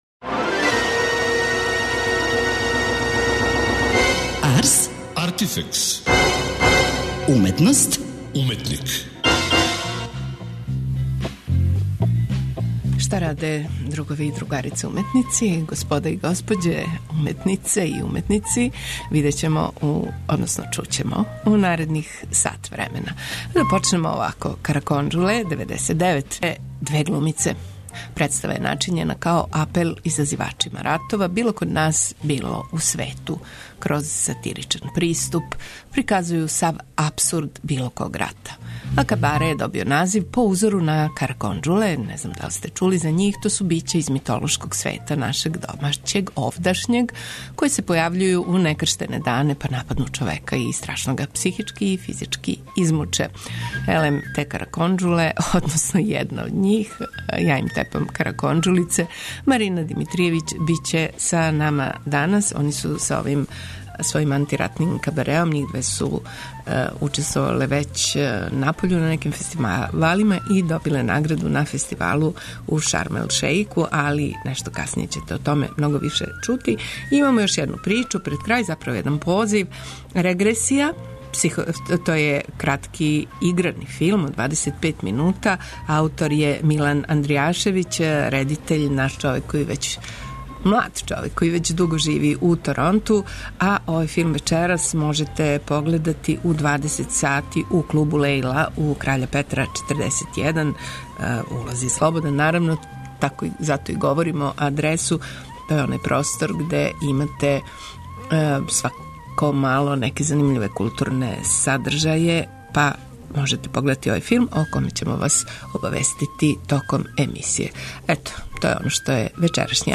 Тим поводом, гошће данашње емисије су глумице